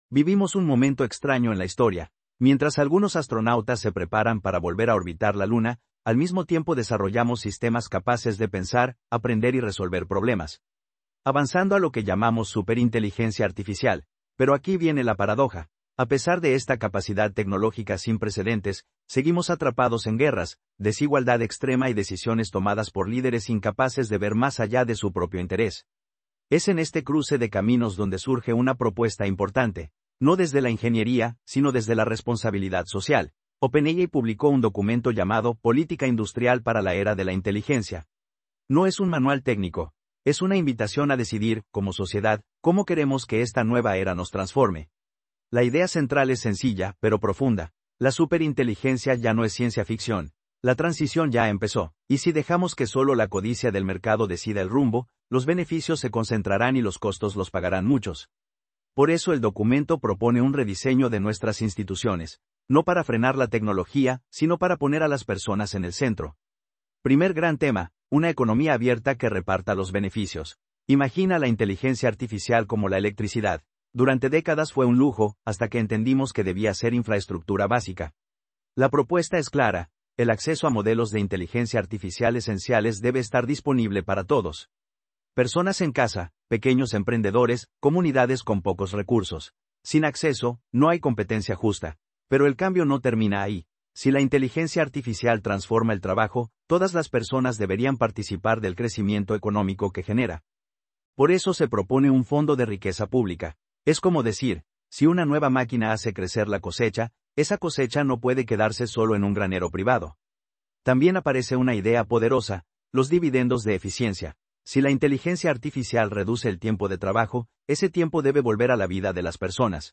Resumen en audio